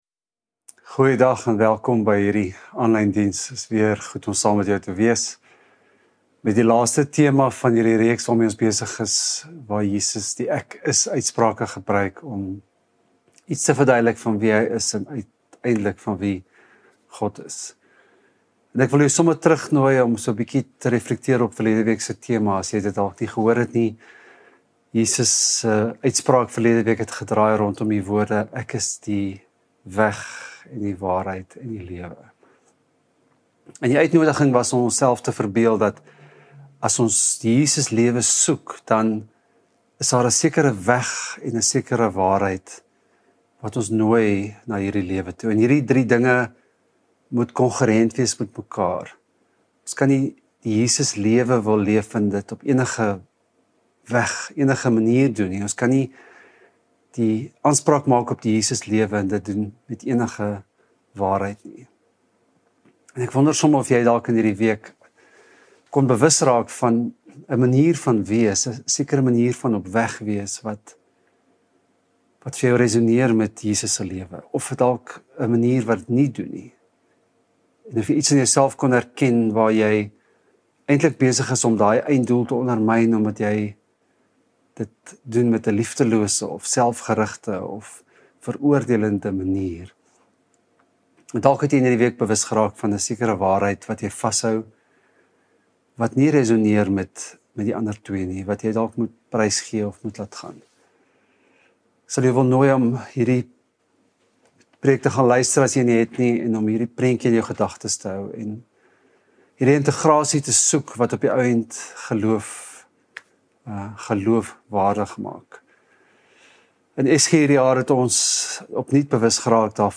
Stellenbosch Gemeente Preke 17 November 2024 || God Is...